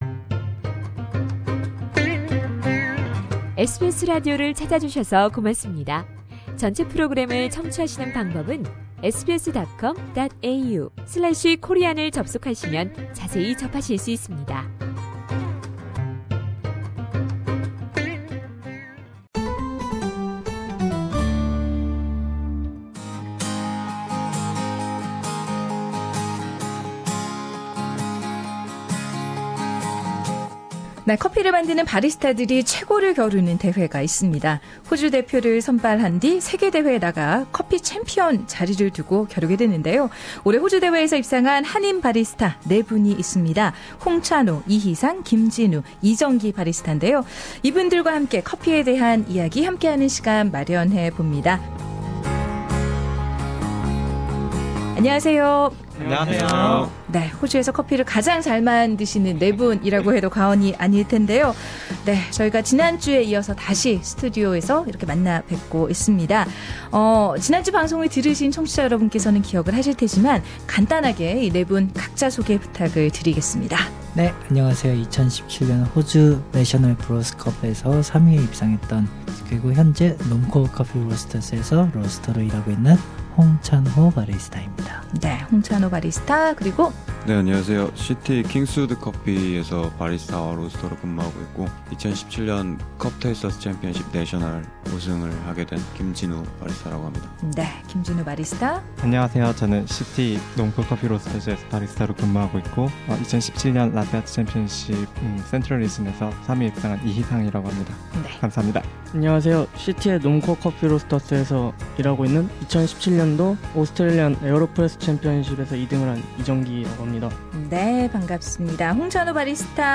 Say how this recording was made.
Four award-winning Korean baristas joined SBS studio to share their outstanding achievement and distinguished passion for coffee.